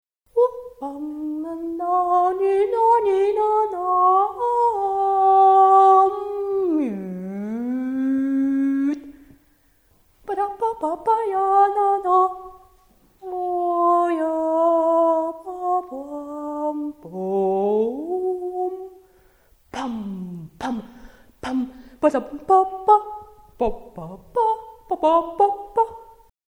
Click to hear Stereo audio track... (Recording in the studio) Click to view larger image...